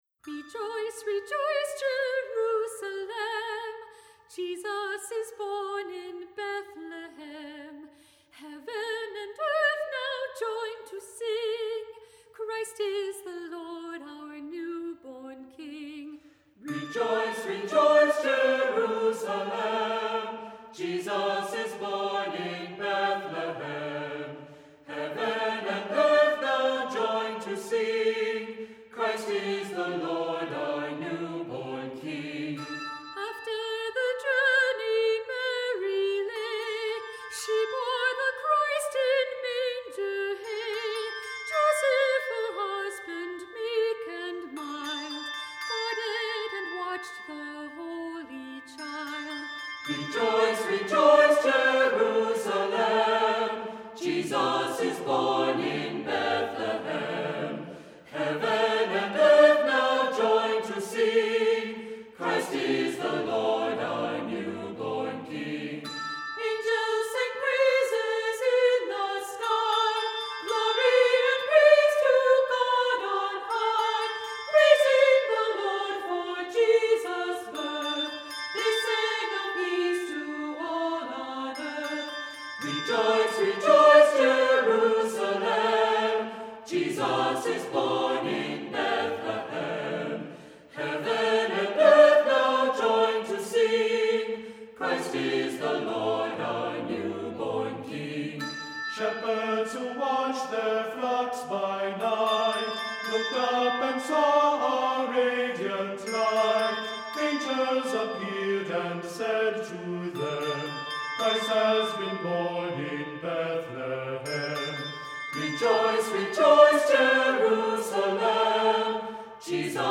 Voicing: SATB and Handbells